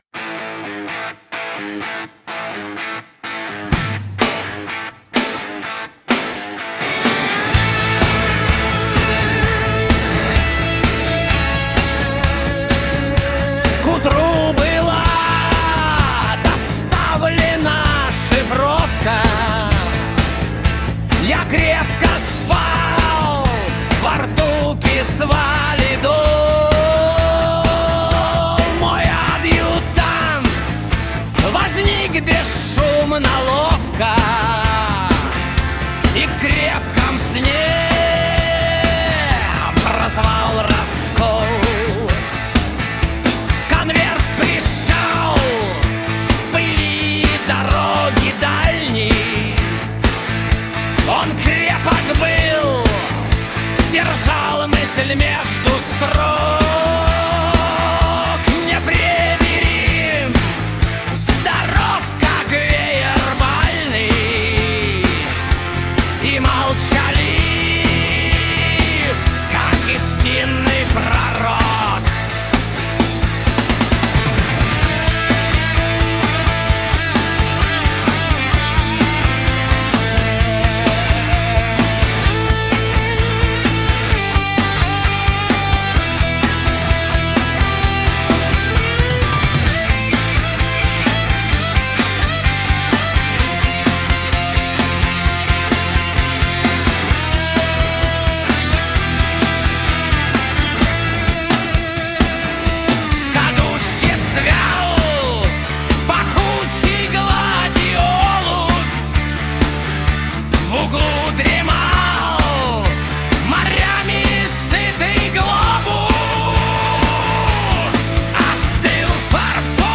Рок-н-ролл сонет, Поэзия / Лирика